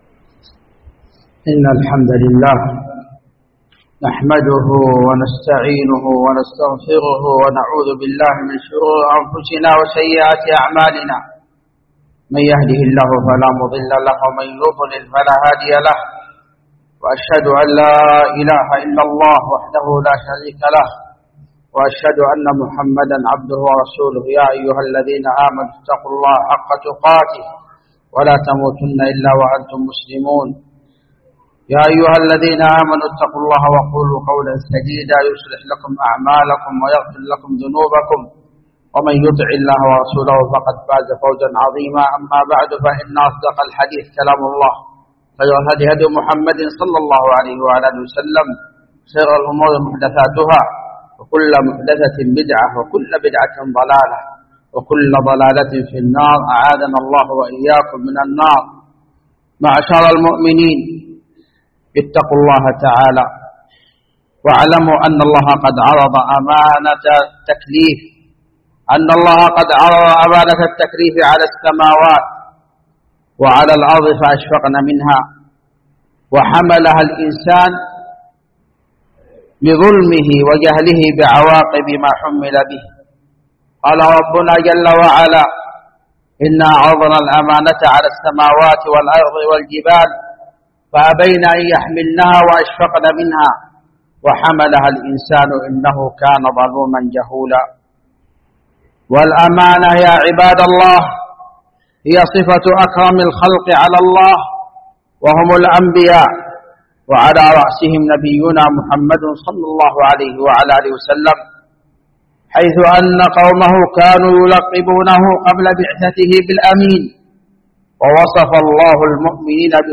جامع الملك عبدالعزيز باسكان الخارش بصامطة
مواعظ ورقائق